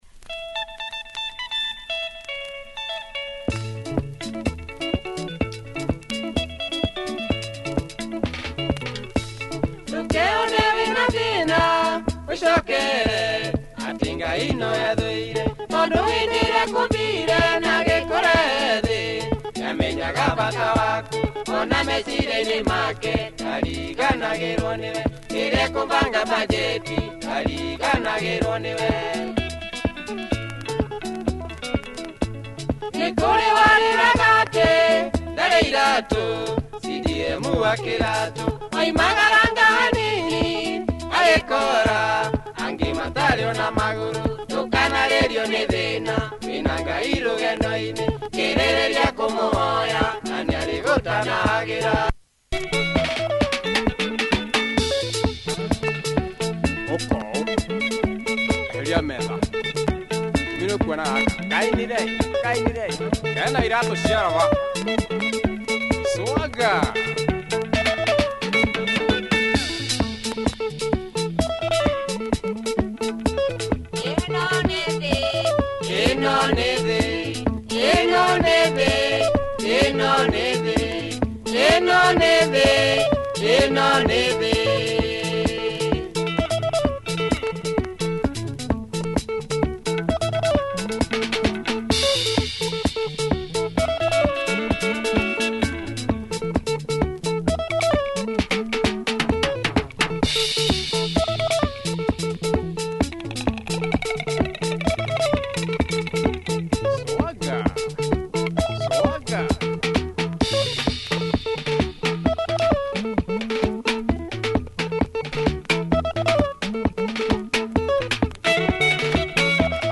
Good punchy beat